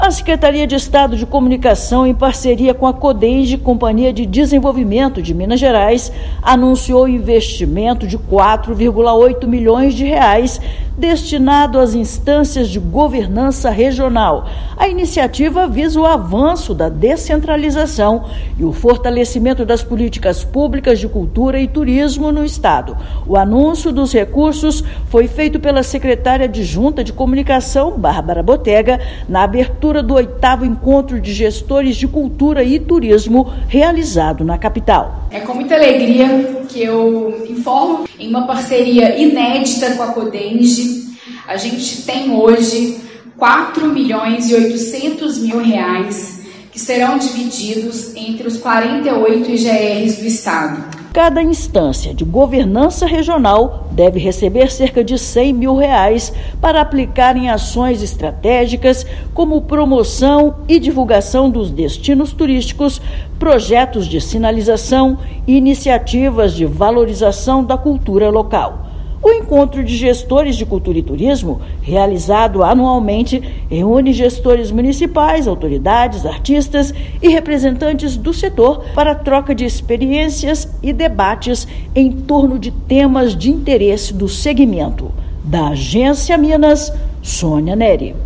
O encontro reúne autoridades, gestores culturais, artistas, representantes do setor turístico para debates em torno de temas de interesse do segmento. Ouça matéria de rádio.